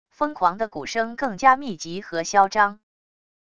疯狂的鼓声更加密集和嚣张wav音频